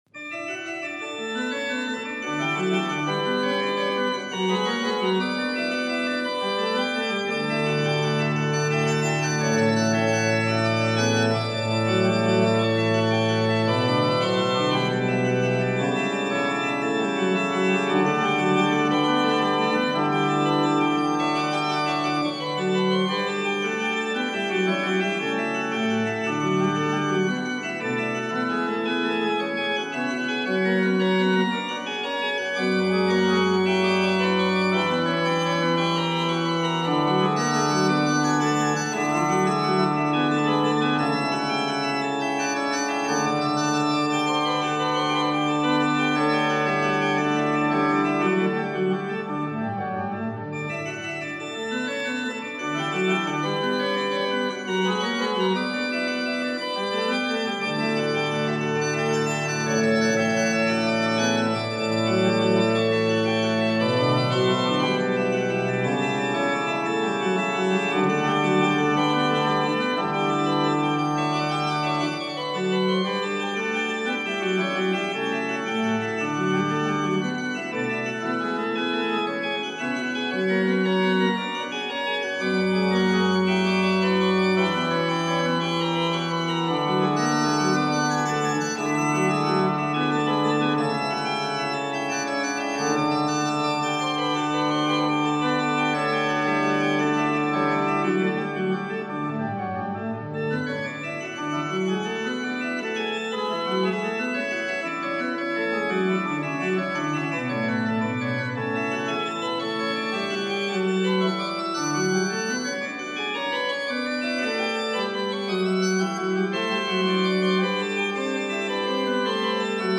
Freiberg Cathedral Small Organ
small organ Freiberg Cathedral